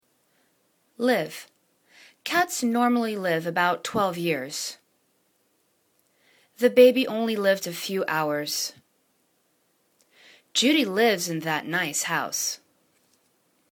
live    /liv/    v